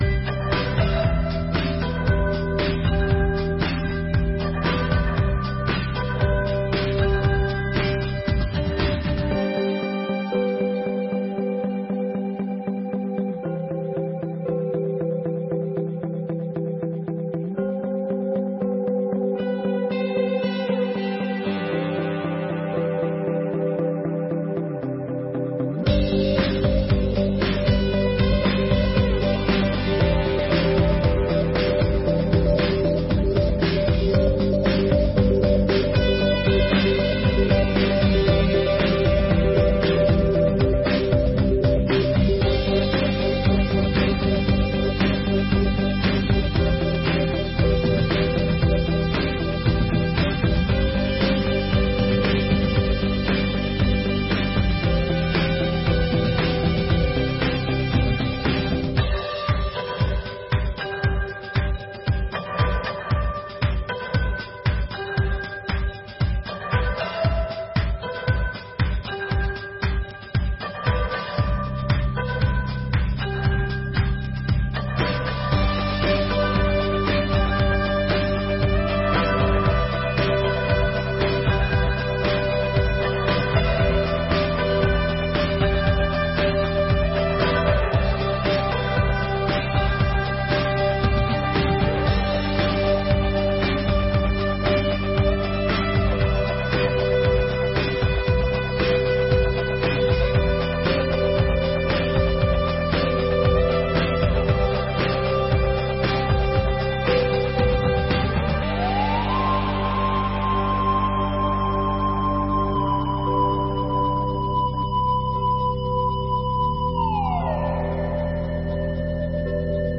34ª Sessão Ordinária de 2024